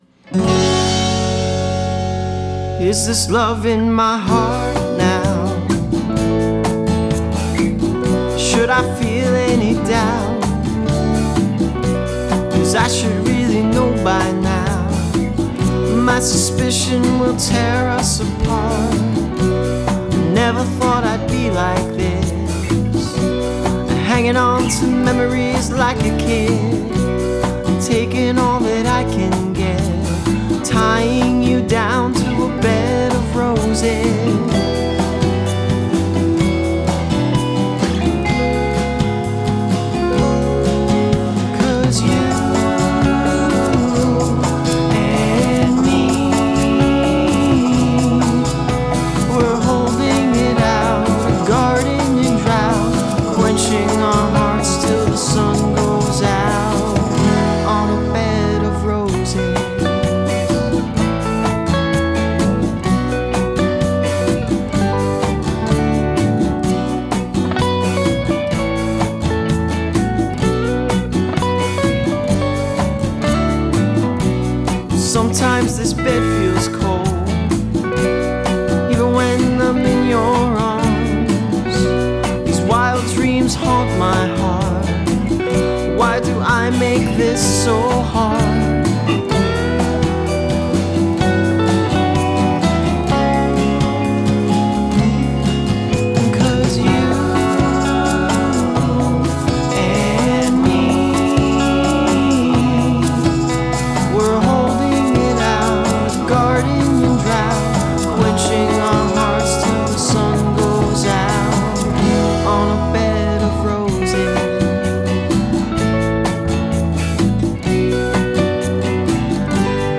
Here's a song I wrote and recorded, mixed and mastered myself.